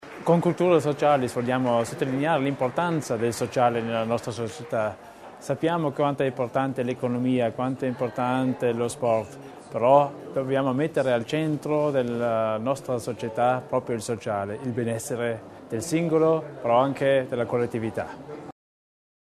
L’Assessore Theiner sull’importanza del progetto “Cultura Socialis”